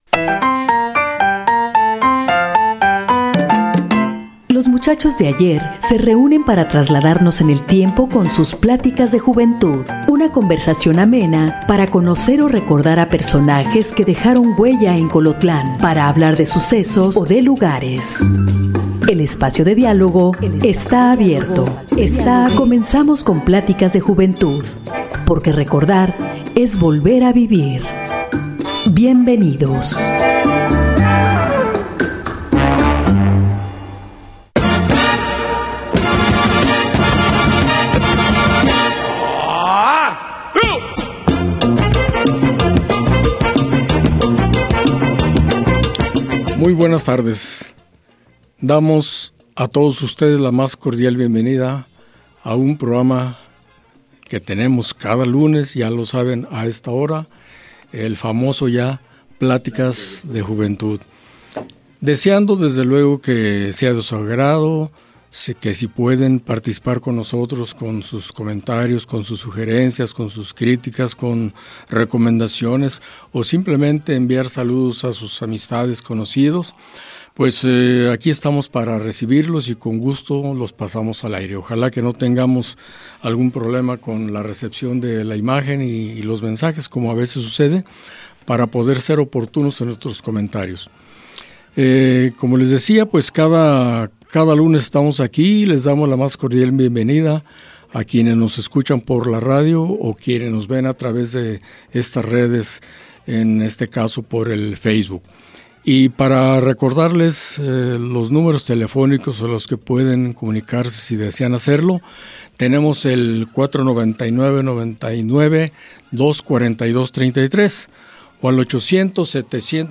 Rescatar la riqueza oral de los pueblos para trasmitirla a nuevas generaciones a través de una plática amena e informal, es cometido principal del programa Pláticas de juventud, donde se escucha la voz de la experiencia y se reviven recuerdos de diferentes generaciones de hombres colotlenses. La vida del pueblo narrada por voces masculinas.